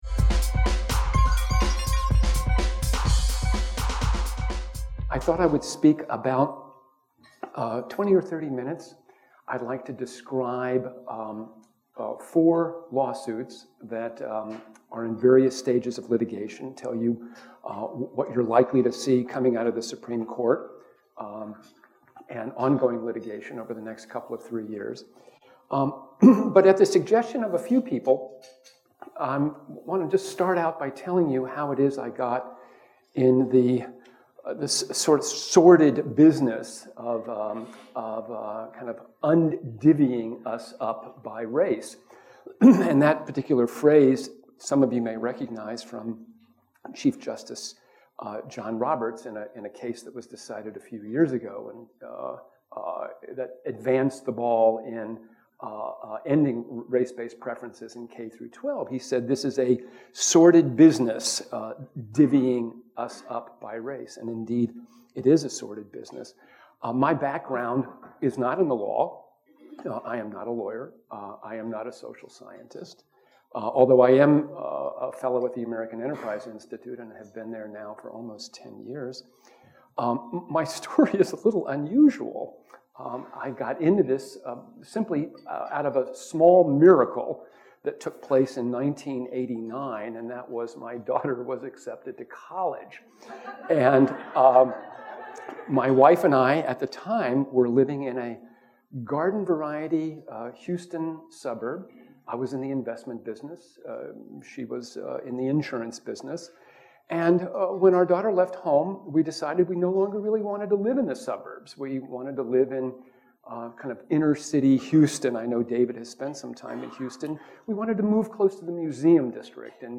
Blum spoke at Reason Weekend , the annual donor event for the nonprofit that publishes this website , about how certain states' voting districts are gerrymandered into racially homogeneous zones, and detailed four lawsuits pending before the courts pertaining to race-based legislation.